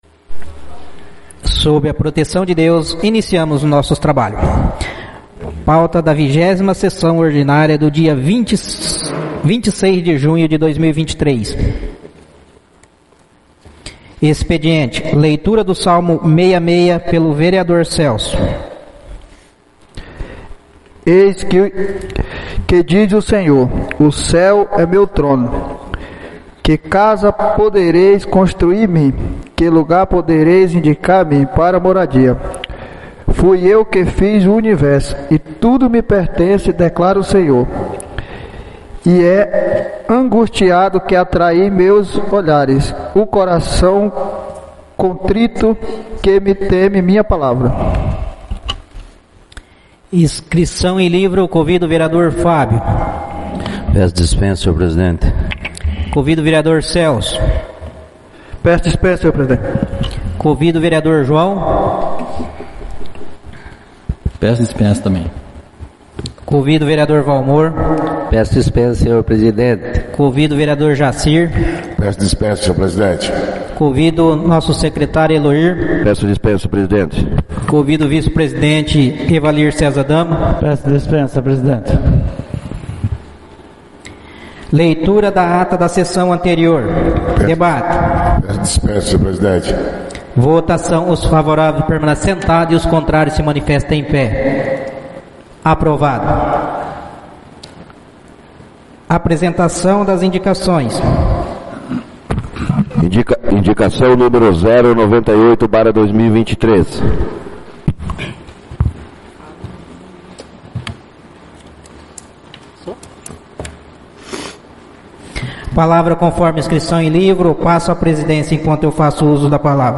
20ª SESSÃO ORDINÁRIA - 26/06/2023